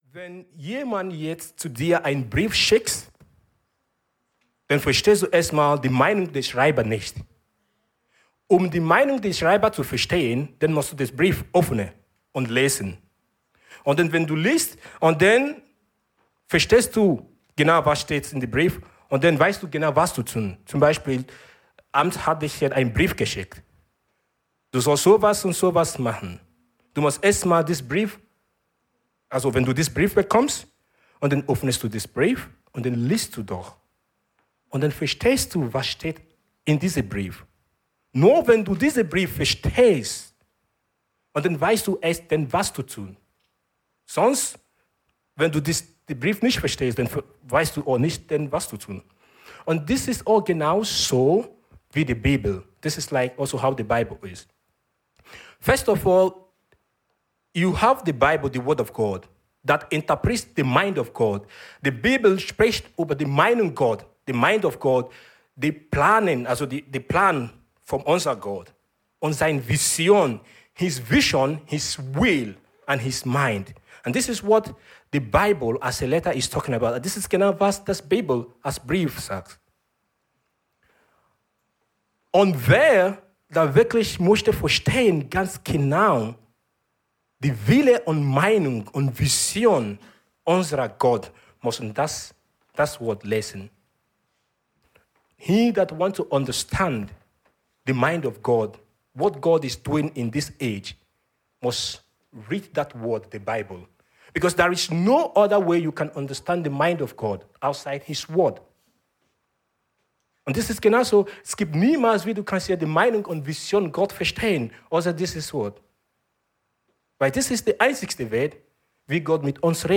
Kingdom times ~ Predigten vom Sonntag Podcast
Alle Predigten aus den Sonntagsgottesdiensten